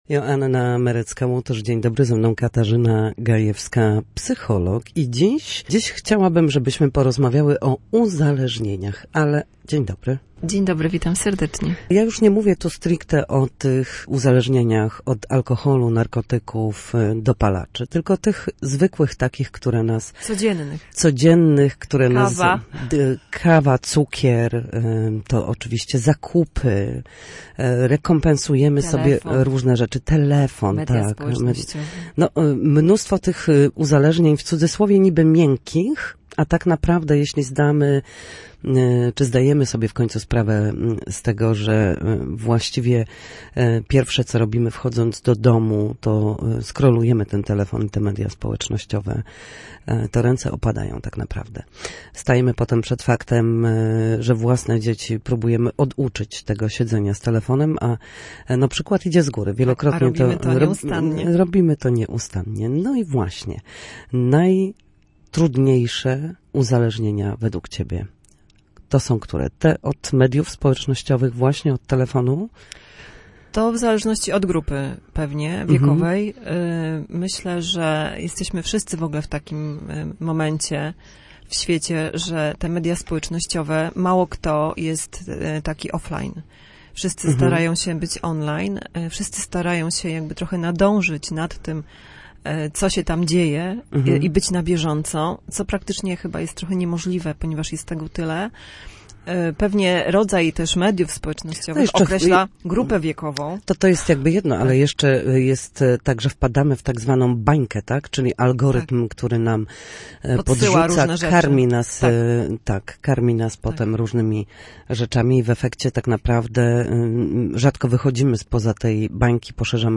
W każdą środę, w popołudniowym paśmie Studia Słupsk Radia Gdańsk, dyskutujemy o tym, jak wrócić do formy po chorobach i urazach. W audycji Na zdrowie nasi goście – lekarze i fizjoterapeuci – odpowiadają na pytania dotyczące najczęstszych dolegliwości, podpowiadają, jak leczyć się w warunkach domowych, oraz zachęcają do udziału w nowych projektach.